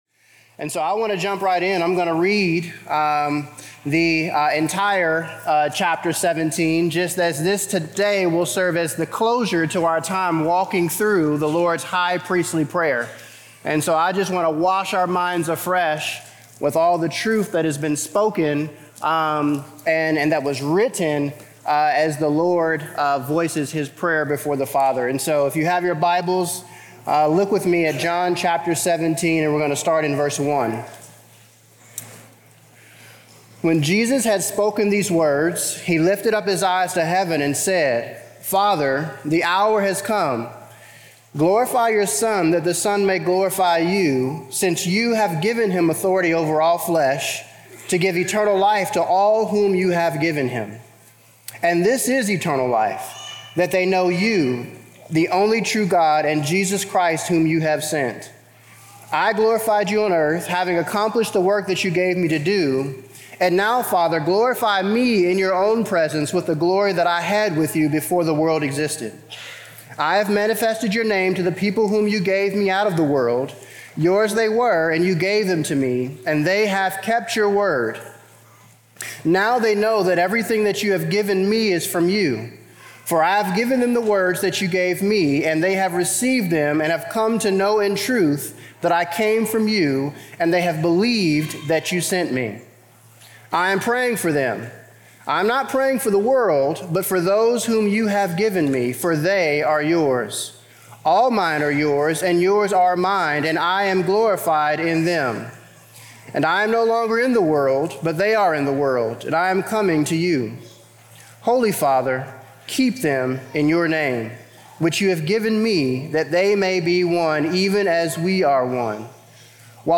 Sermons | Immanuel Baptist Church